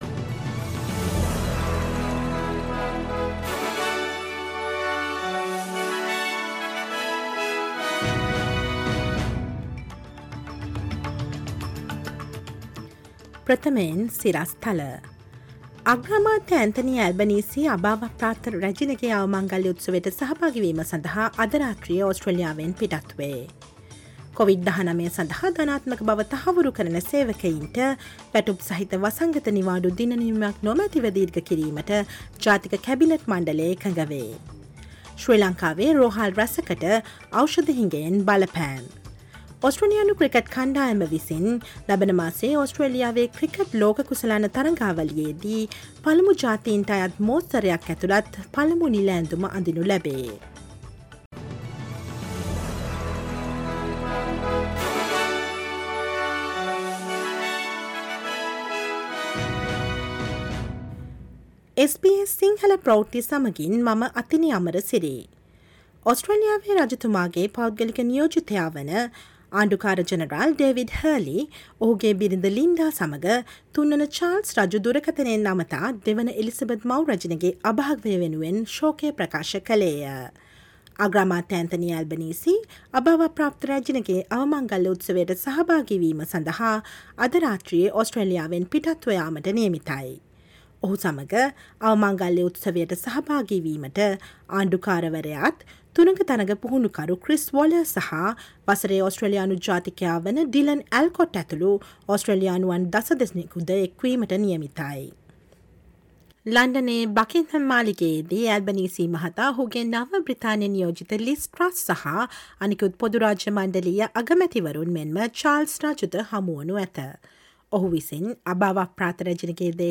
Listen to the SBS Sinhala Radio news bulletin on Thursday 15 September 2022